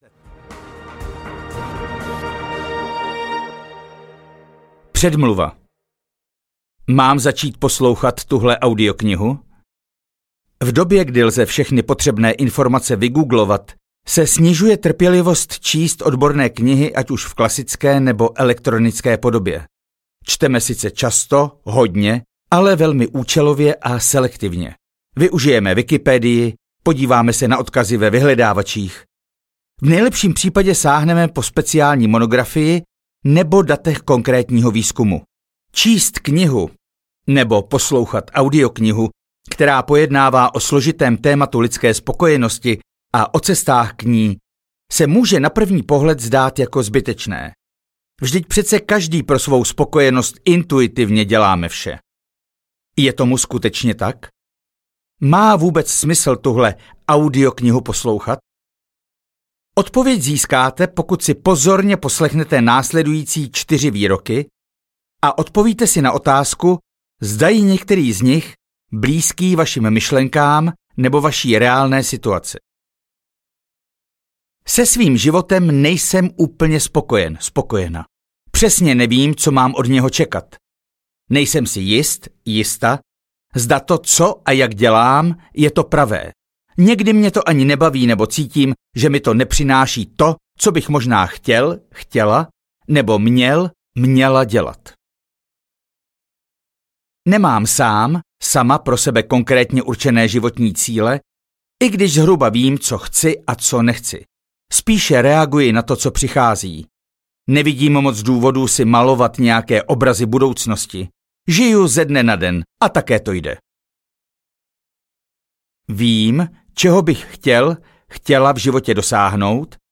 Stručný manuál úspěšného lenocha audiokniha
Ukázka z knihy